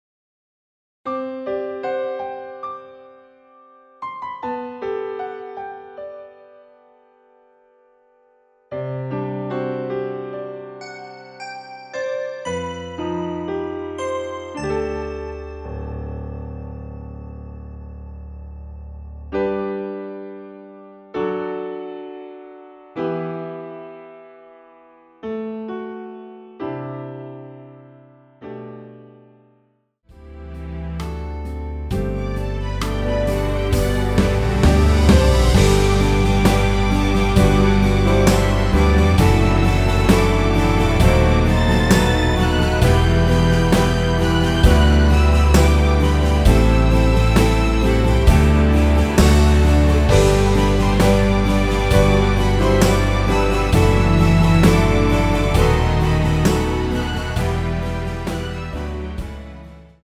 대부분의 남성분이 부르실수 있는키로 제작 하였습니다.(미리듣기 참조)
앞부분30초, 뒷부분30초씩 편집해서 올려 드리고 있습니다.
중간에 음이 끈어지고 다시 나오는 이유는